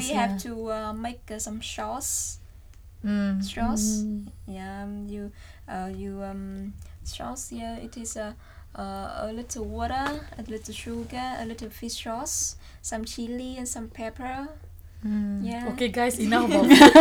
FBrun = female from Brunei MThai = male, from Thailand
FMal : mmm FViet : yeah FBrun : okay guys enough <1> about food </1> FMal : <1> @@@ </1> Intended Word: sauce Heard as: shorse ? Discussion: Viet has [ʃ] rather than [s] at the start of this word.